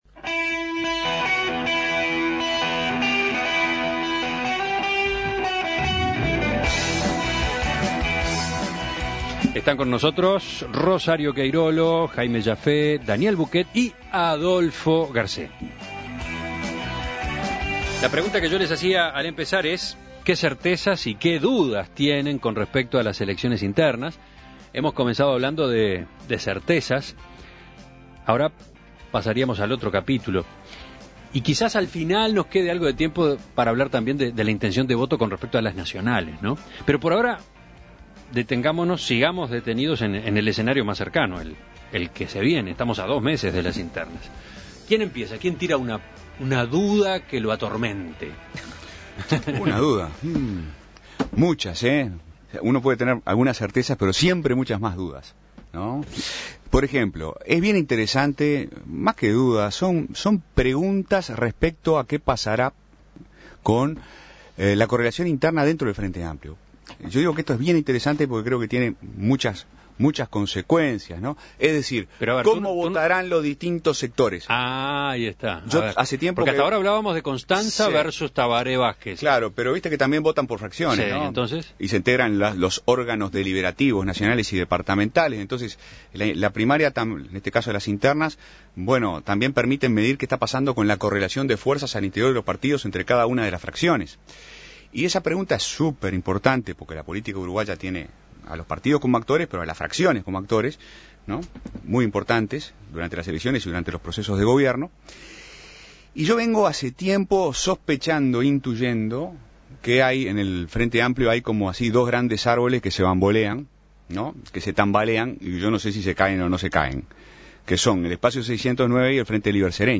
Tertulia de politólogos: ¿Qué "certezas" y qué preguntas tienen con respecto al proceso electoral una vez superada la instancia de las internas?